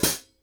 Drum Samples
hat31.ogg